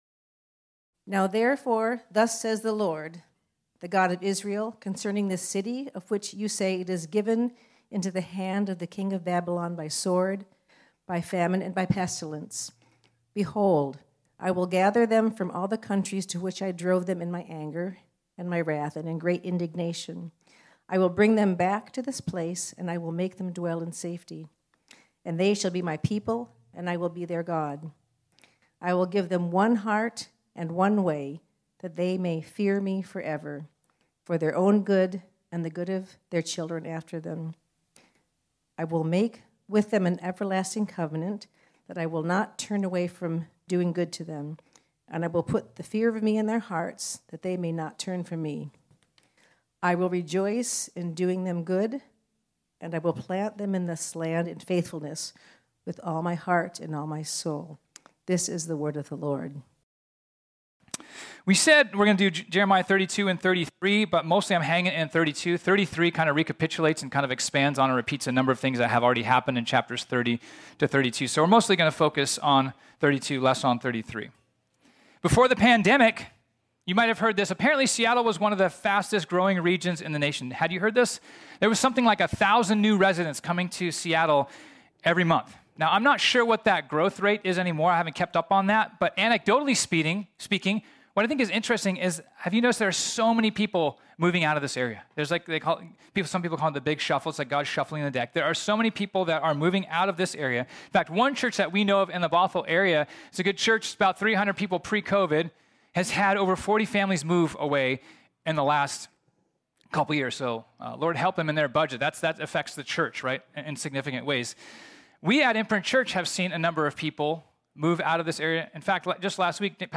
This sermon was originally preached on Sunday, July 17, 2022.